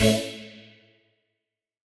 Index of /musicradar/future-rave-samples/Poly Chord Hits/Straight
FR_T-PAD[hit]-A.wav